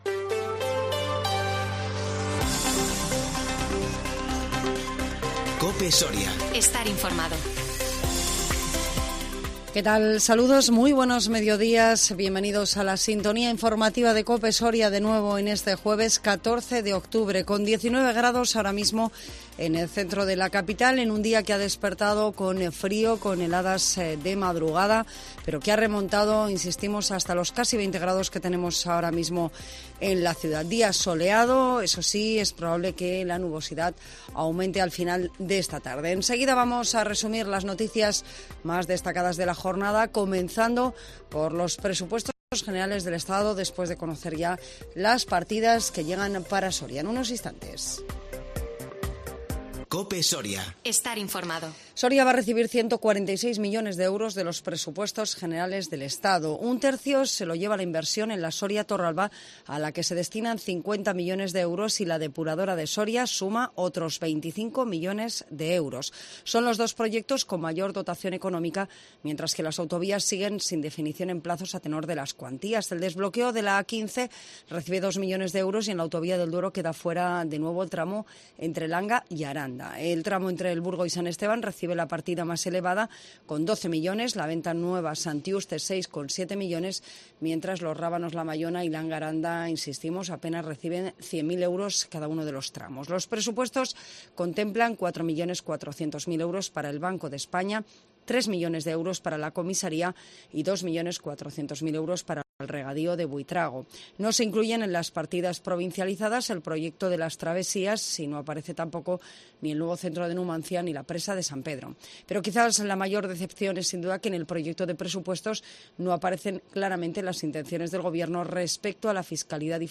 INFORMATIVO MEDIODÍA 14 OCTUBRE 2021